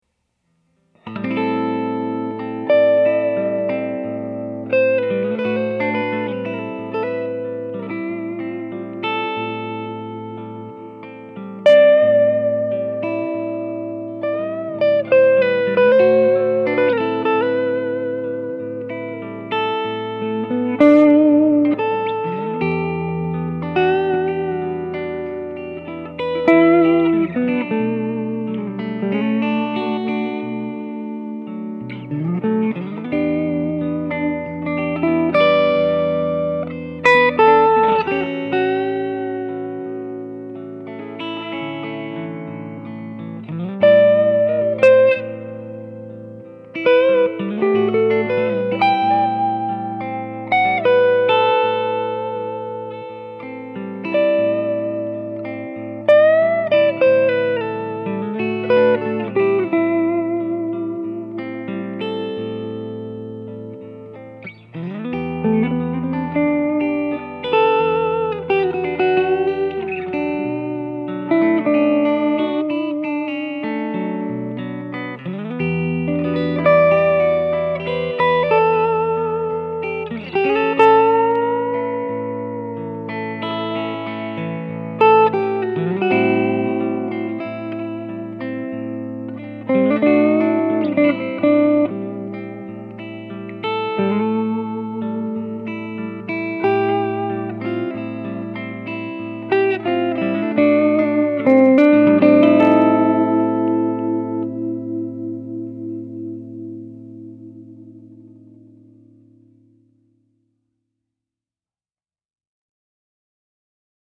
I probably should’ve had a before and after clip, but what those tubes did was to take the naturally bright tone of the amp, and add a bit of bottom end to it. The amp is still on the bright side, but with that little bit of extra bottom end, it sounds so much richer. Here’s  the amp totally clean, played with Goldie, then into a Hardwire reverb, then into the amp. The tones are simply lush!